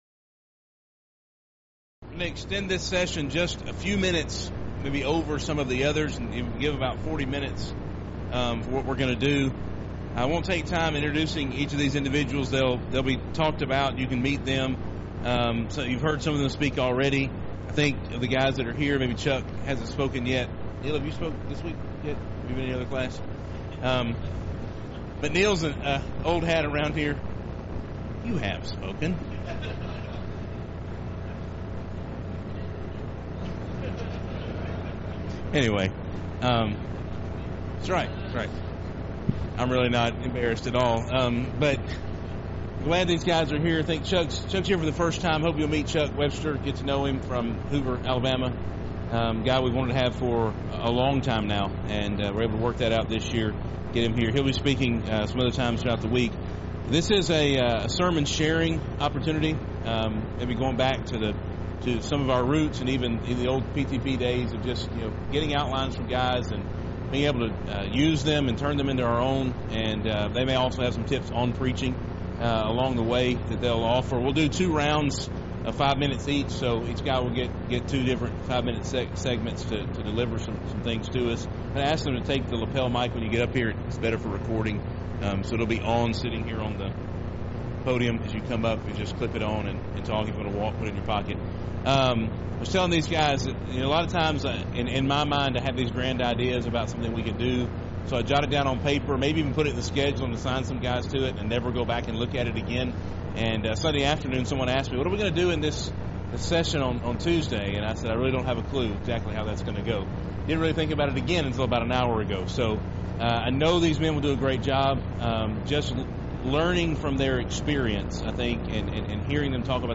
Event: 2018 Focal Point Theme/Title: Preacher's Workshop